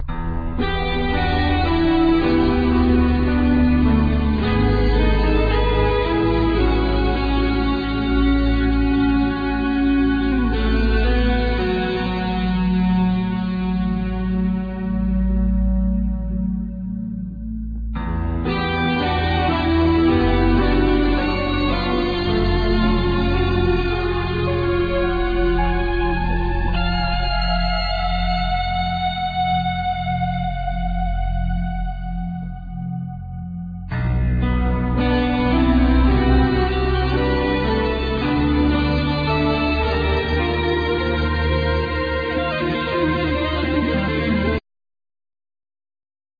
Guitars(Electric,Acoustic)
Keyboards
Bass
Drums